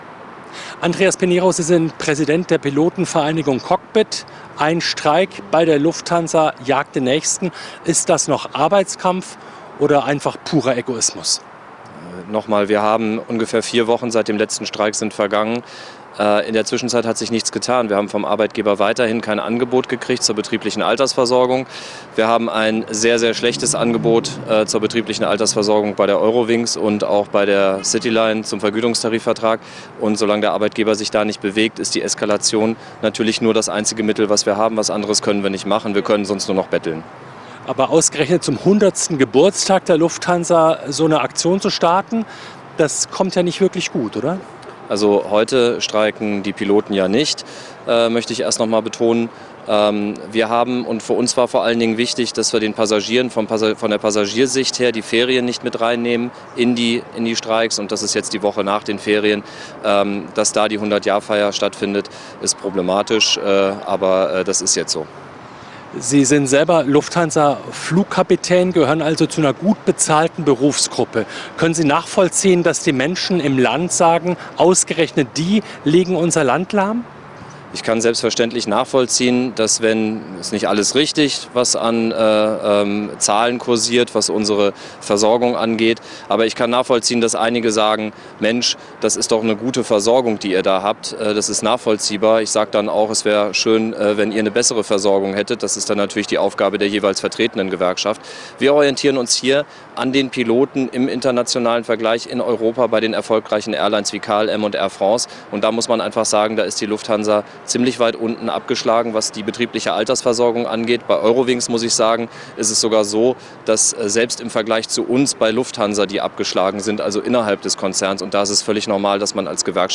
er im ntv-Interview mit Ulrich Reitz.